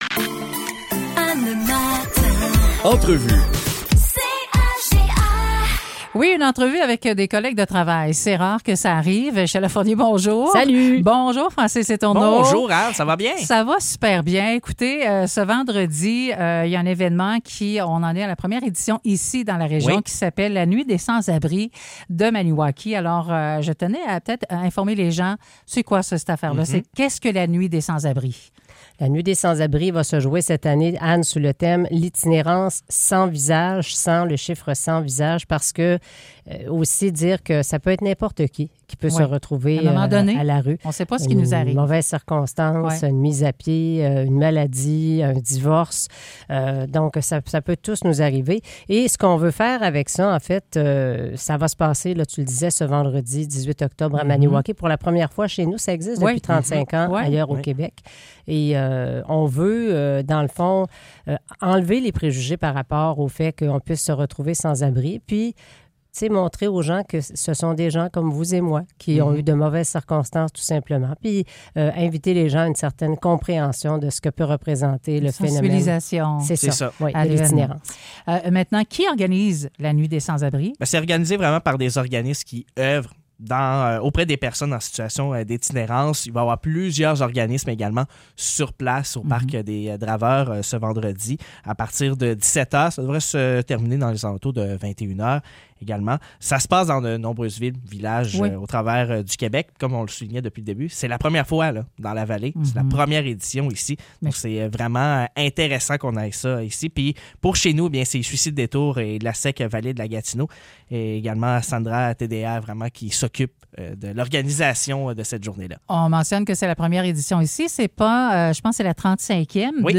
Entrevue sur La Nuit des sans-abri